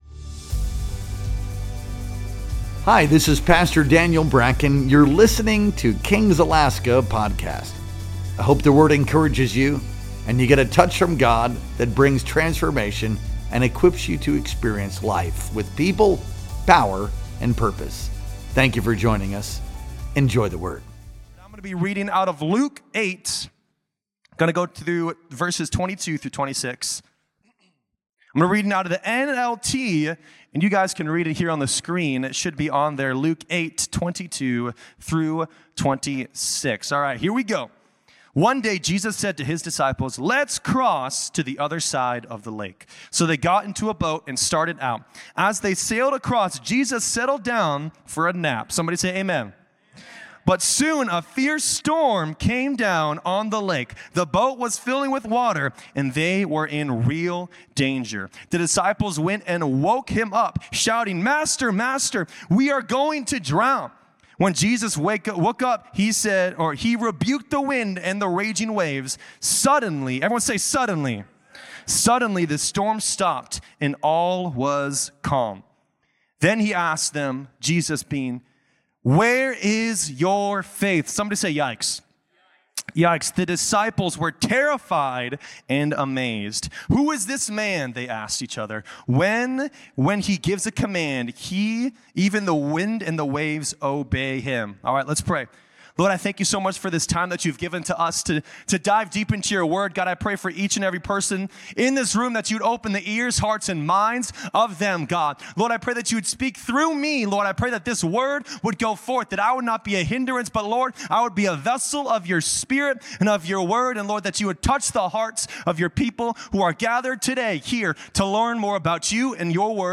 Our Sunday Night Worship Experience streamed live on November 2nd, 2025.